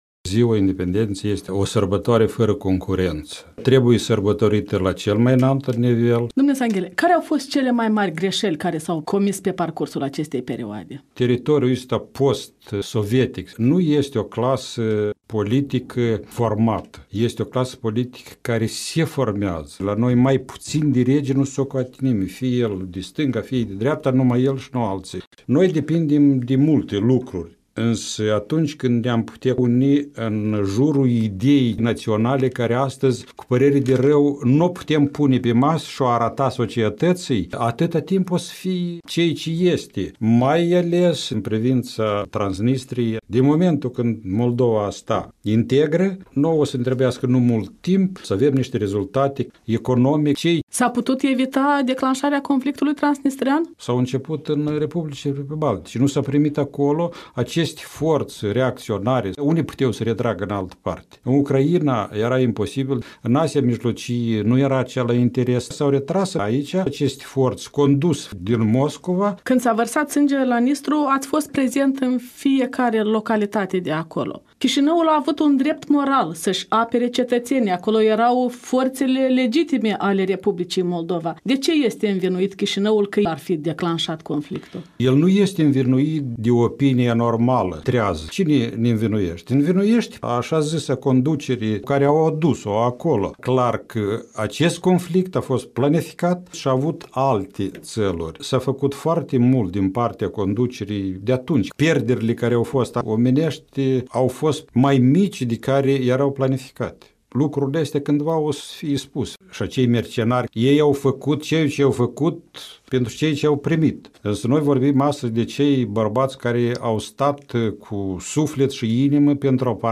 Un interviu cu Andrei Sangheli